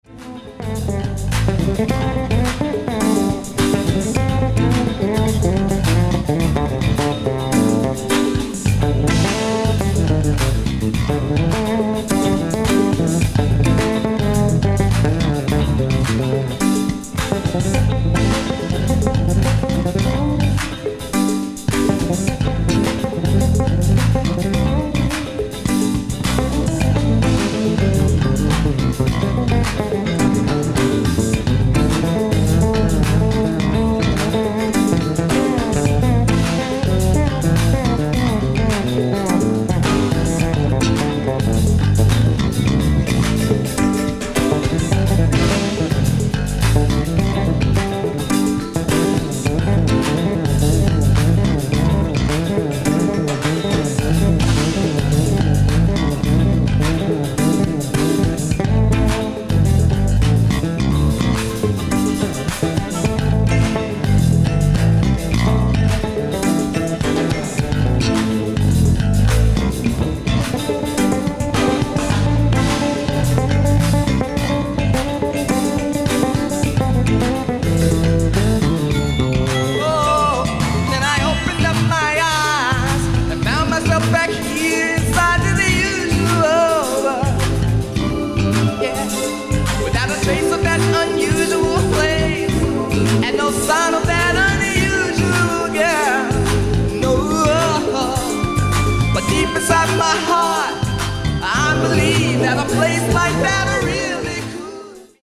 ライブ・アット・東京 08/05/1988
※試聴用に実際より音質を落としています。